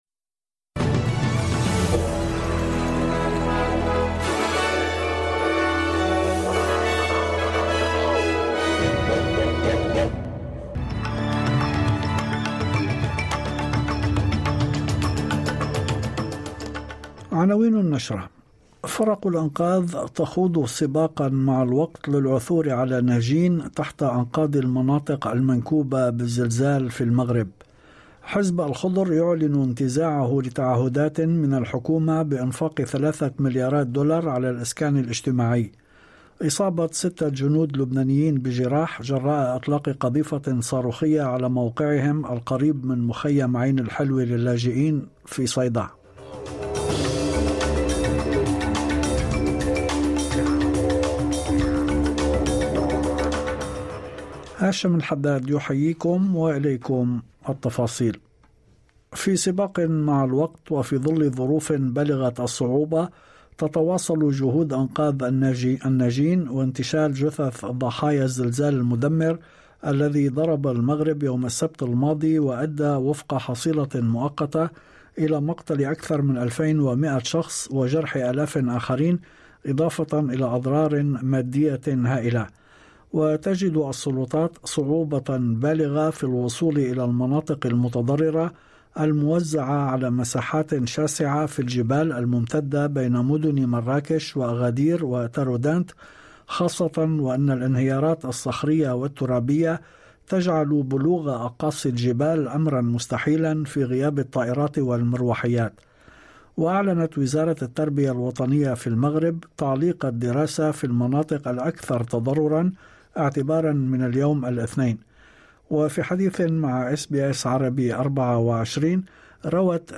نشرة أخبار المساء 11/9/2023